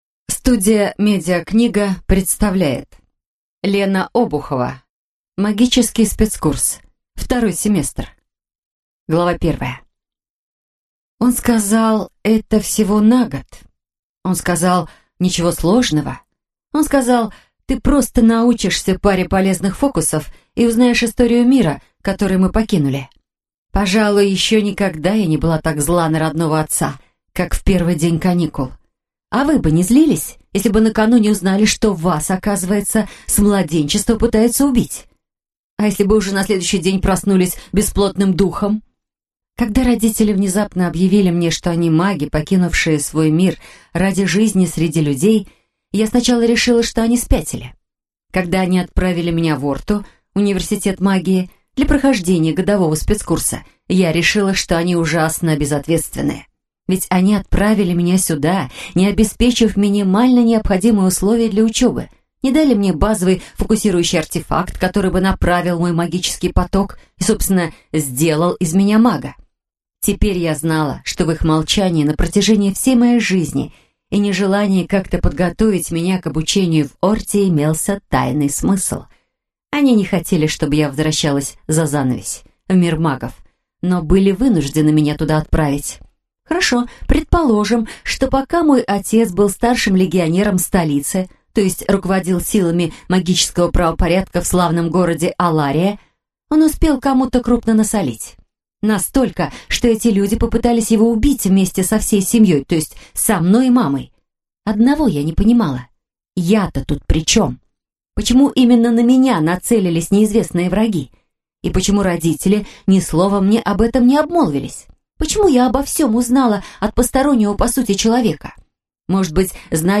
Аудиокнига Магический спецкурс. Второй семестр | Библиотека аудиокниг